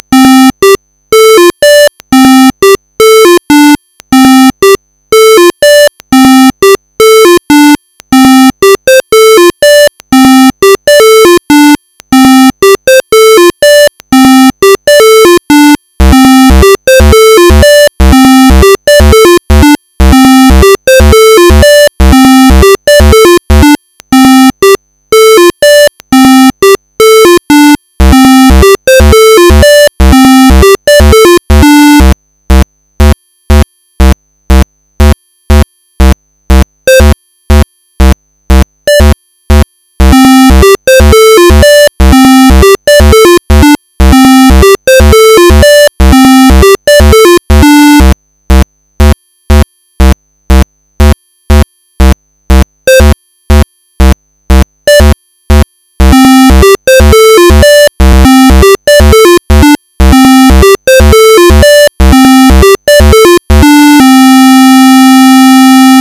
Tiene el rms a -0,8dBFs.
Es punzante y puede que malo para la salud, y la melodía robótica impertinente... enloquecedora.
Una onda cuadrada perfecta!!!!!!!!!!!